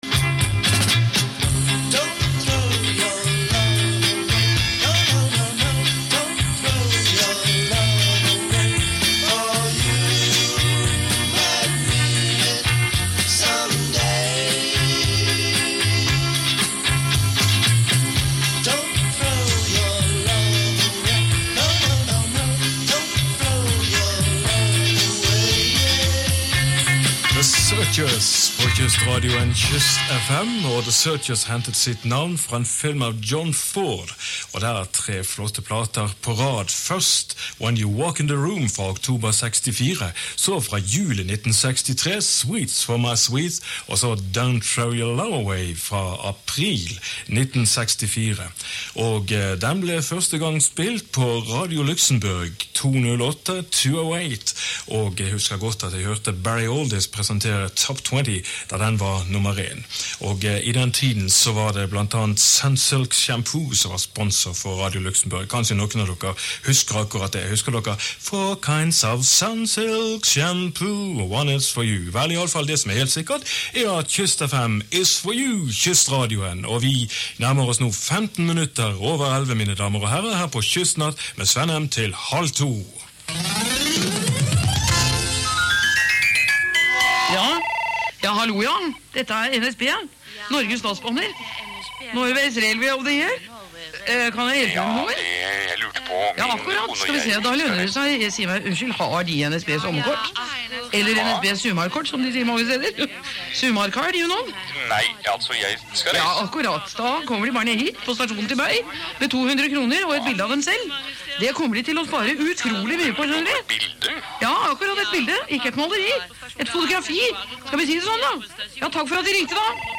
Utdrag av Talkshow med katolsk-orienterte prester fra Dnk.